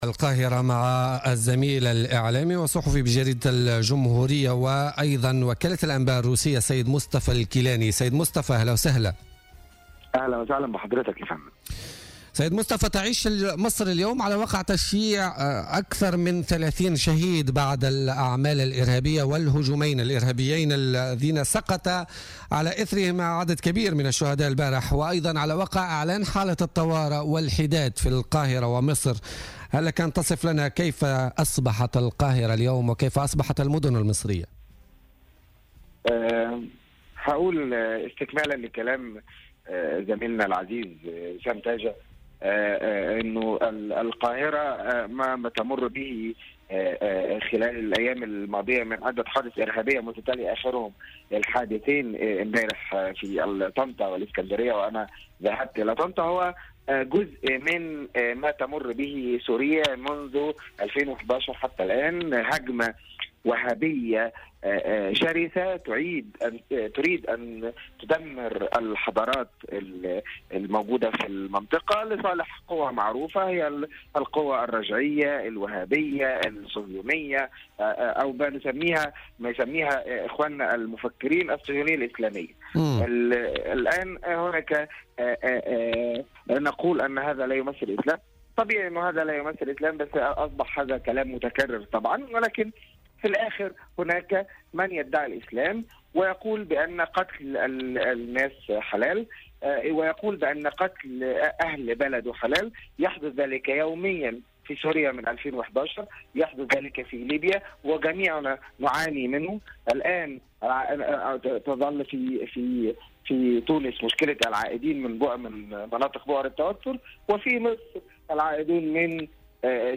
وقال في اتصال هاتفي مع "الجوهرة أف أم" عبر "بوليتيكا"، إن مصر تعاني من تداعيات العائدين من بؤر التوتر وأن هذه الضربة التي أسفرت عن سقوط أكثر من 40 ضحية تأتي بعد انتعاشة السياحة المصرية. وأوضح أنه تم استهداف مسيحيي مصر حتى تكون ضربة قاصمة للسياحة المصرية مشيرا إلى ان كل المصريين بمسلميهم ومسيحييهم كفار بالنسبة لهذه التنظيمات الارهابية .